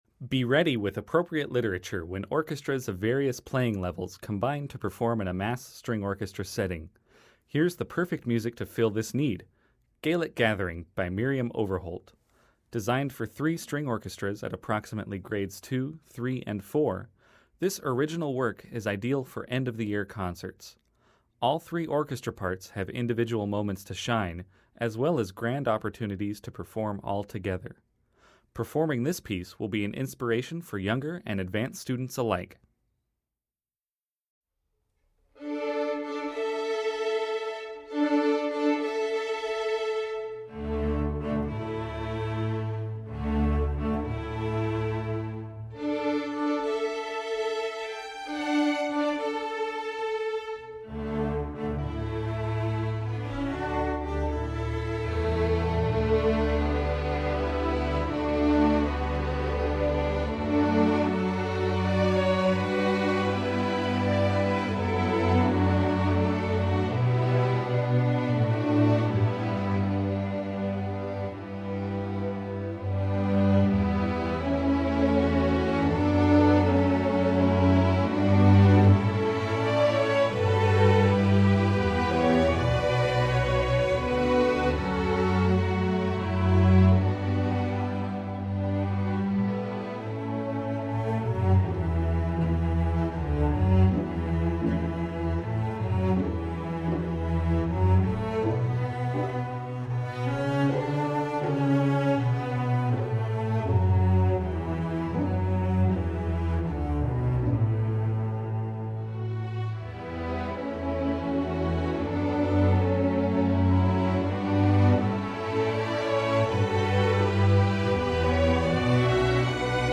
Voicing: String Orchestra L